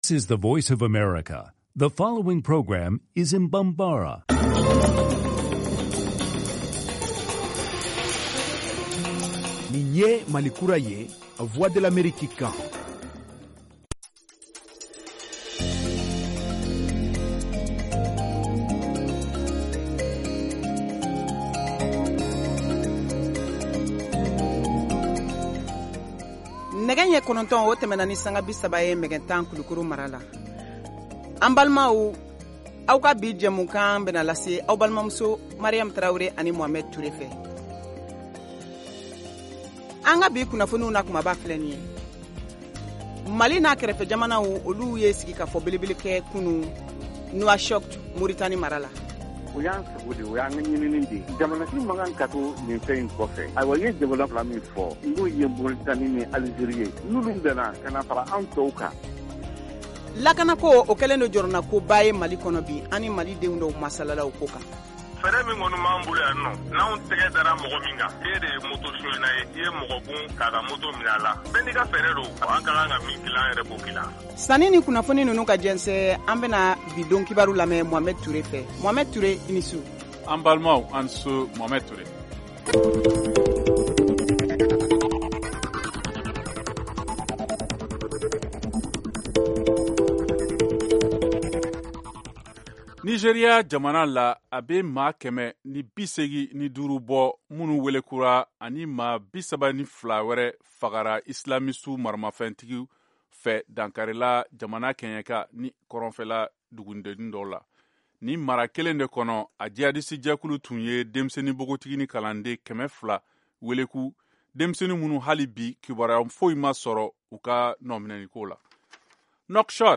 Emission quotidienne
en direct de Washington, DC, aux USA